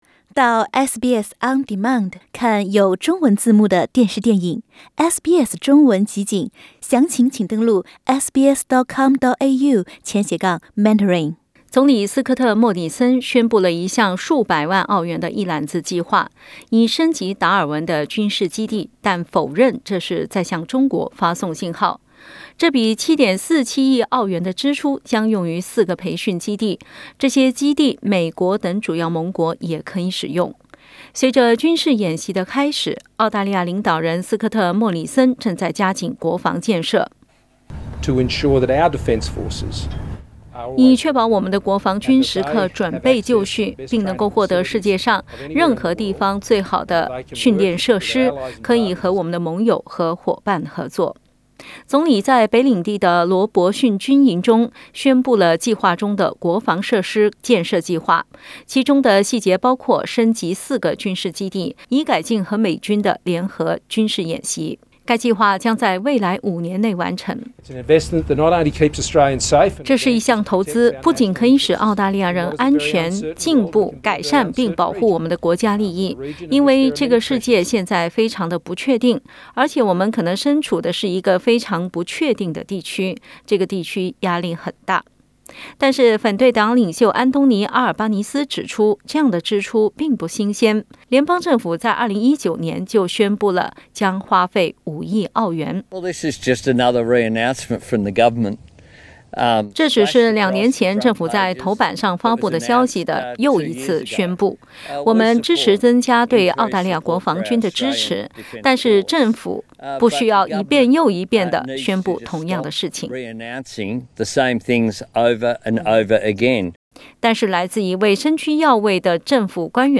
（請聽報道） 澳大利亞人必鬚與他人保持至少1.5米的社交距離，請查看您所在州或領地的最新社交限制措施。